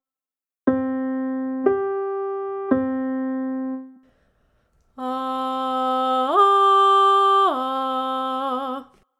Intervals: Thirds & Fifths
3rd: C4 – E4 – C4
5th: C4 – G4 – C4